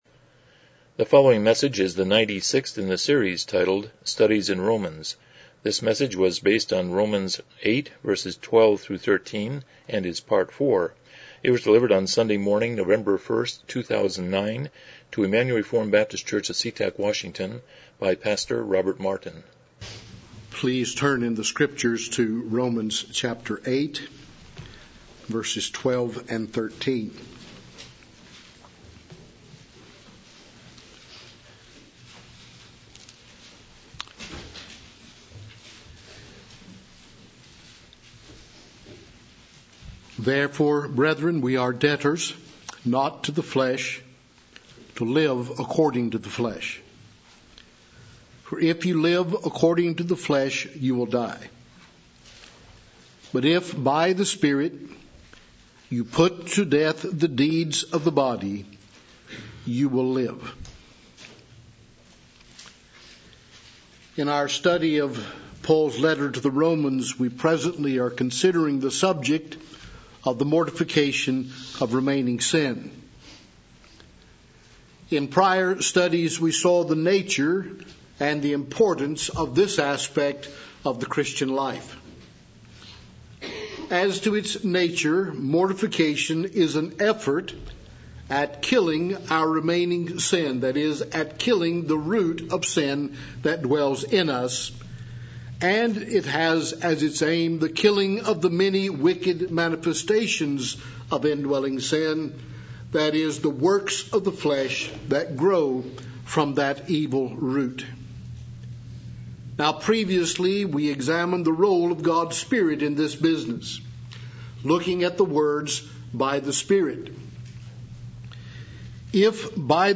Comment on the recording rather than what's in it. Passage: Romans 8:12-13 Service Type: Morning Worship « 54 Preface to the Ten Commandments